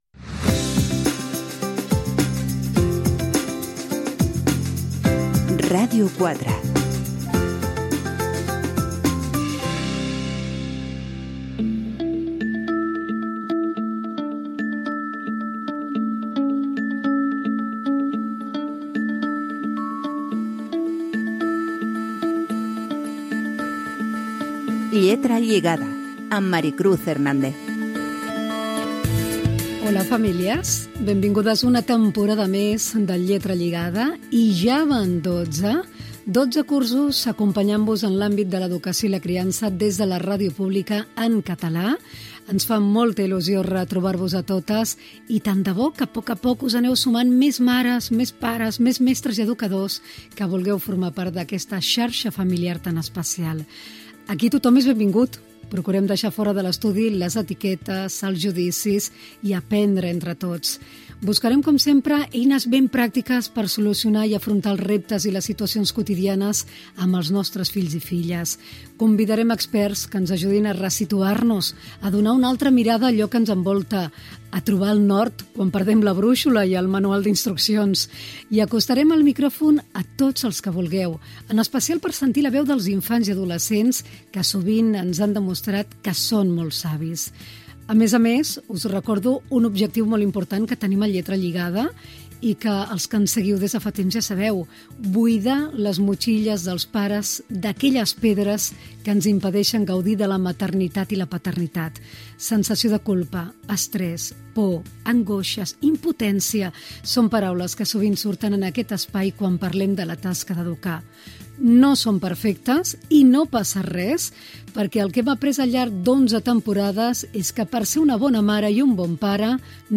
Indicatiu de la ràdio, careta del programa, benvinguda a la temporada número 12.
Divulgació